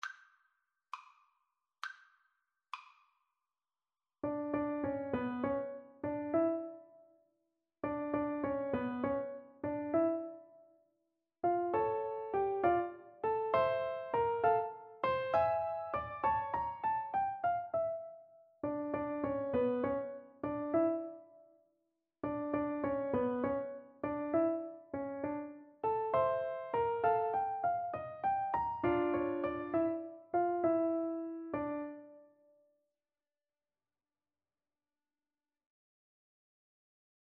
Moderato
Classical (View more Classical Piano Duet Music)